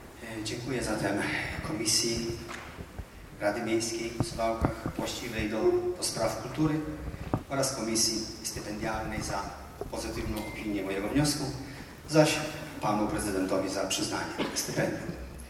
Żywa reakcja publiczności była dla twórców dowodem niewątpliwego sukcesu dzieła.
24 VIII 2021, Suwałki – Sala im. Andrzeja Wajdy Suwalskiego Ośrodka Kultury – Prapremierowe wykonanie „Kantaty o Suwałkach”.